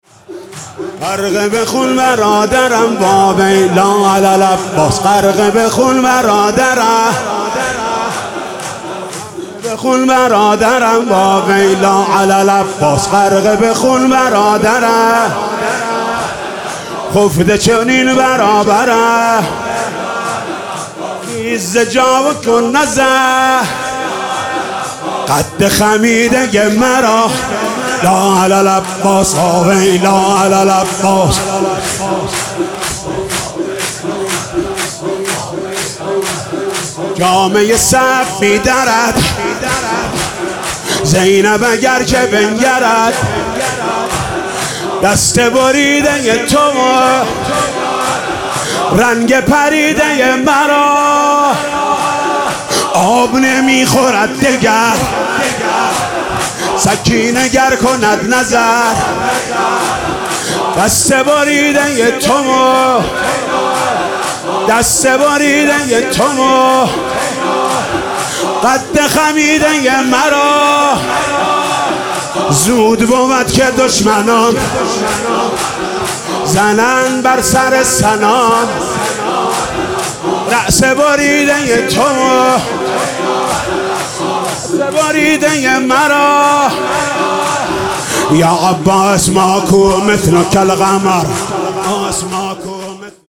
«محرم 1396» (شب تاسوعا) تک: غرق بخون برادرم
«محرم 1396» (شب تاسوعا) تک: غرق بخون برادرم خطیب: حاج محمود کریمی مدت زمان: 00:01:33